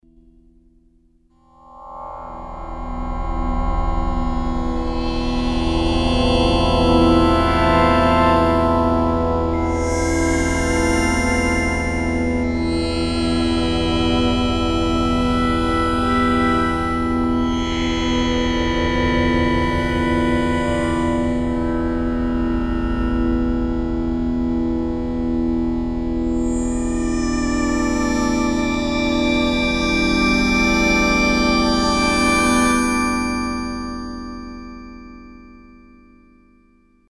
texture pad
Synthesis: FM
pad.mp3